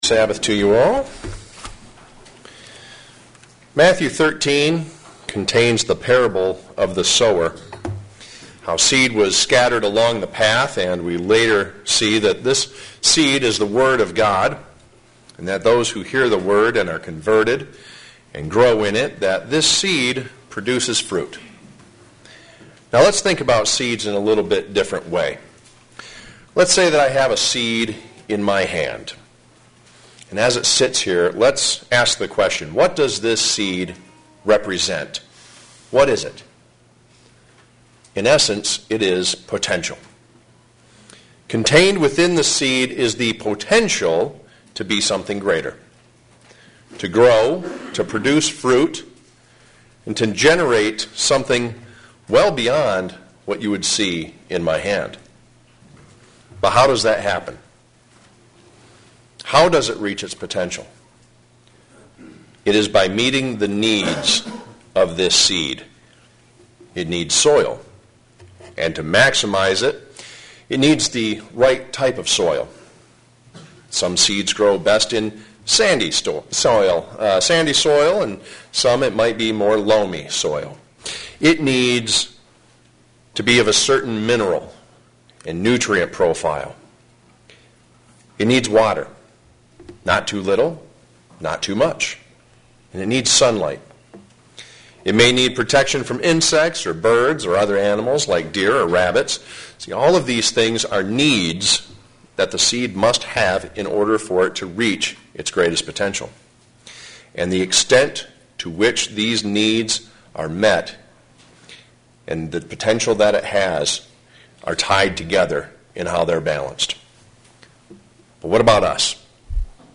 Today, we will look at six of these needs. sermon Studying the bible?